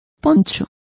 Also find out how ponchos is pronounced correctly.